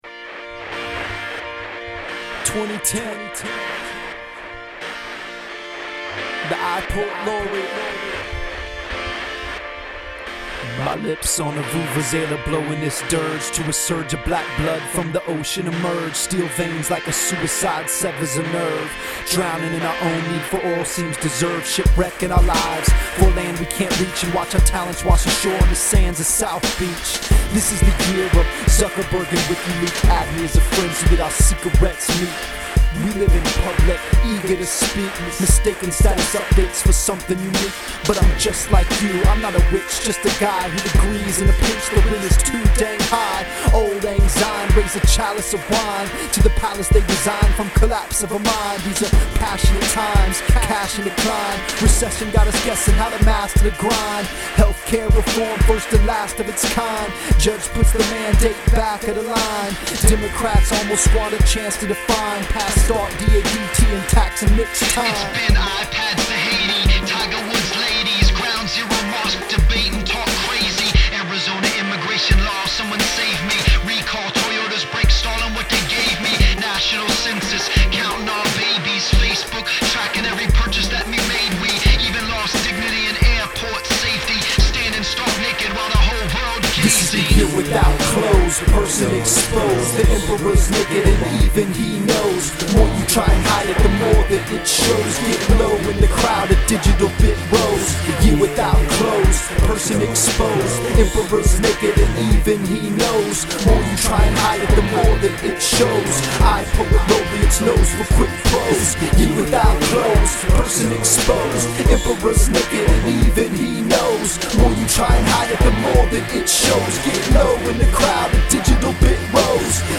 Today’s Rockin’ New Year’s Eve audio here (it has been said, positively I assume, that I’m the Ryan Seacrest of this rap game):